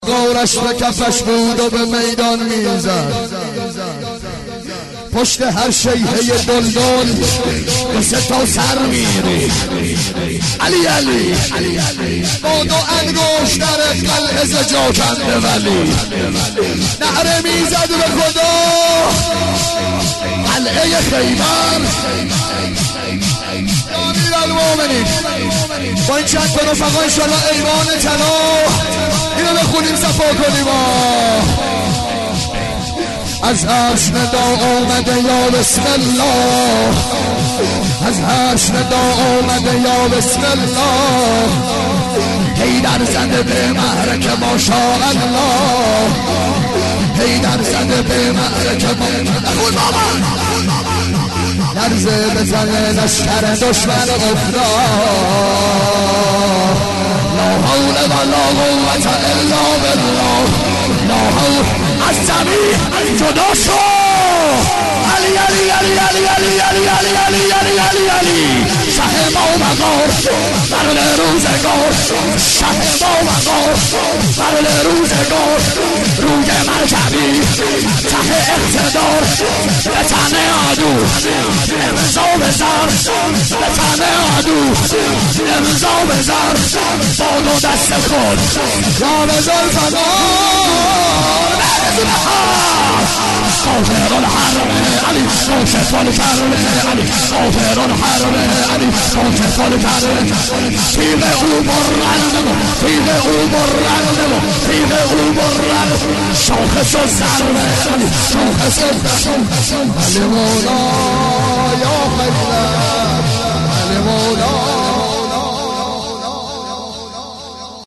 جشن ولادت امام رضا ع